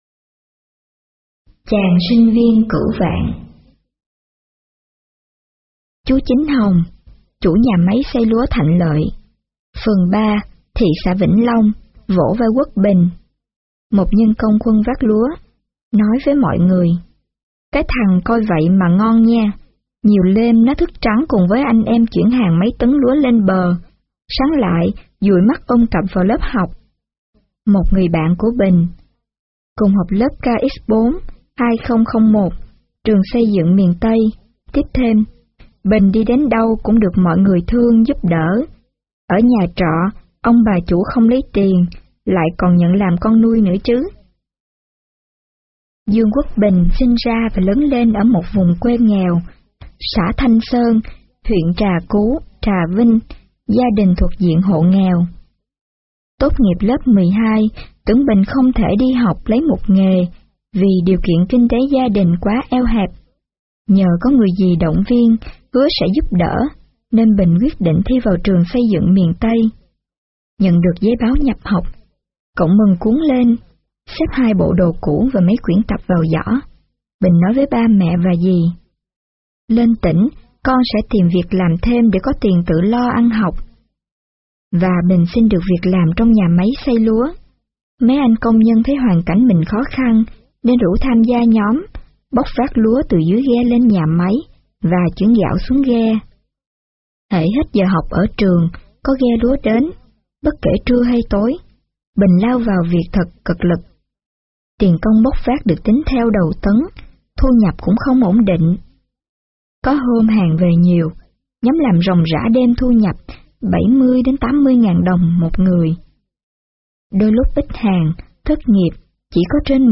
Sách nói | Chàng sinh viên cửu vạn